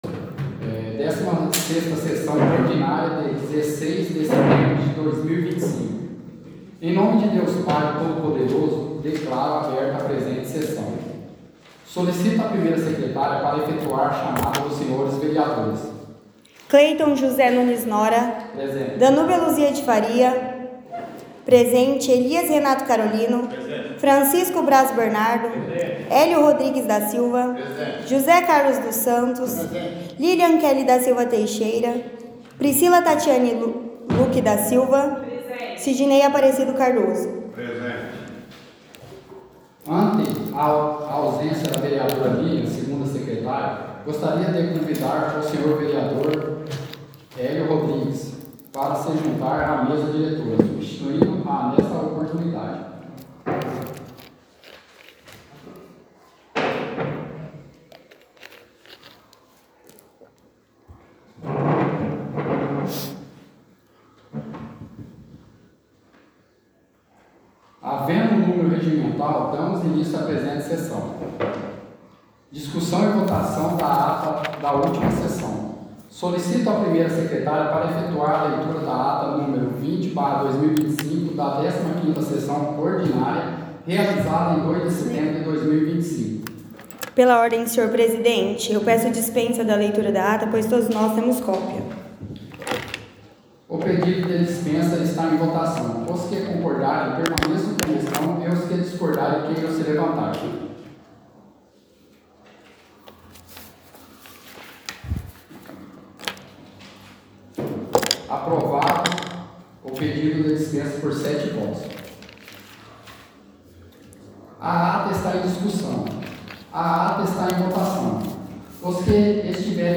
Áudio da 16ª Sessão Ordinária – 16/09/2025